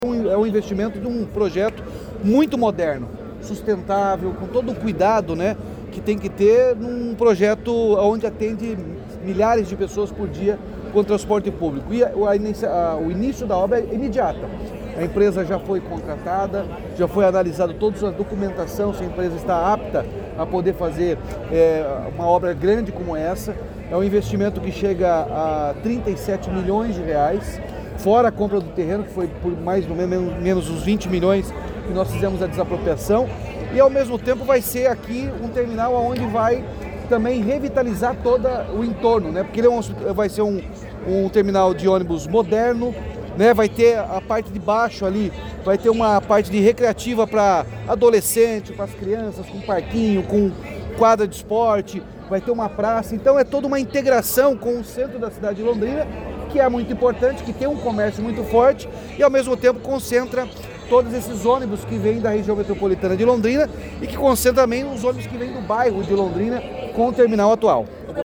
Ouça o que disse o governador: